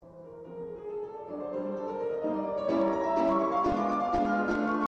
The anguish goes away…then silence…then the arpeggio again, but this time in a new tonality: